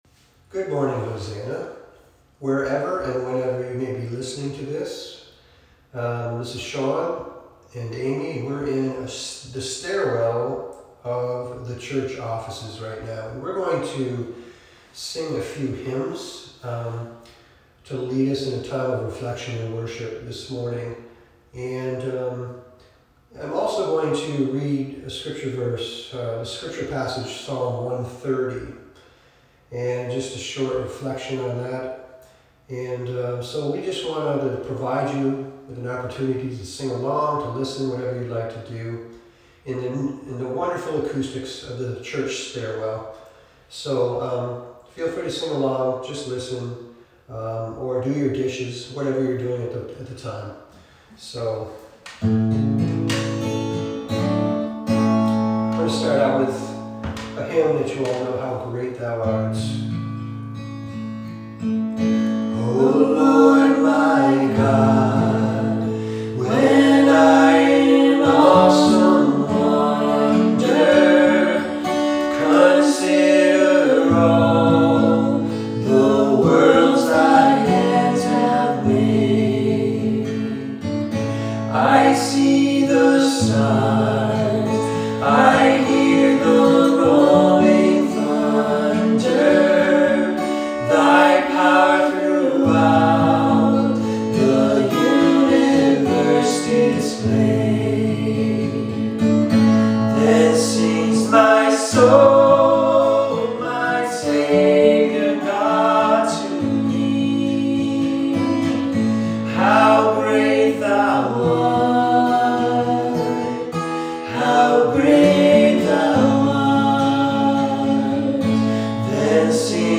some hymns of hope and healing to lift us out of the depths of fear and despair.